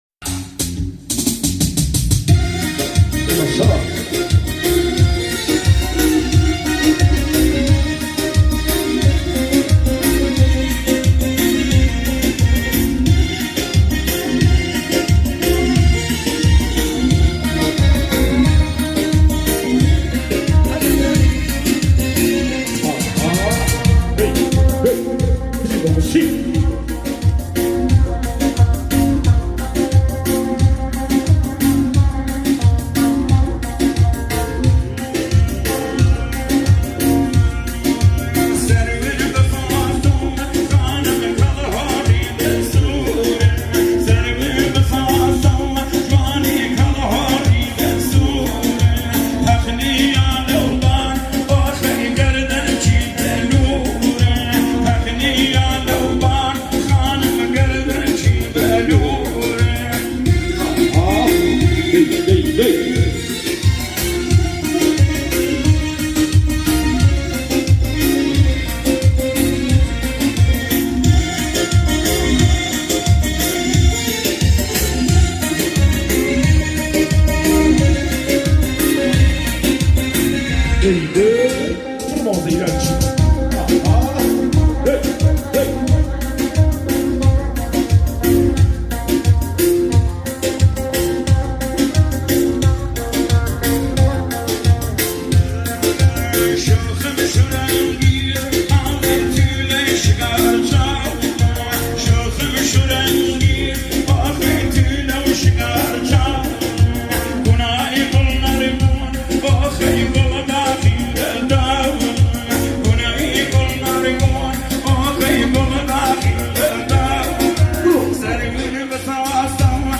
آهنگ کوردی
آهنگ با صدای زن